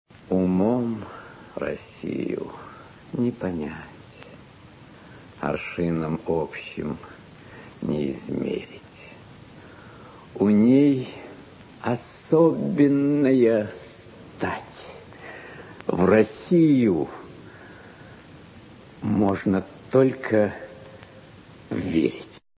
«Умом Россию не понять…». Читает Иннокентий Смоктуновский (скачать)